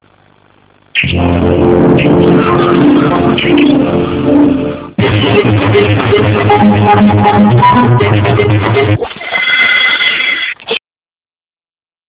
De même pour le fichier de 19h25 il y a un défaut du magnétoscope.